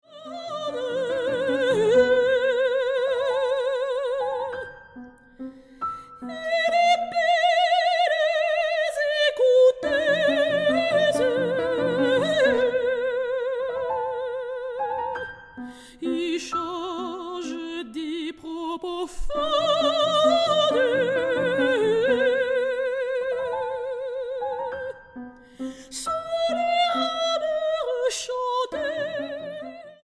para soprano, oboe, clarinete, violín, cello y piano.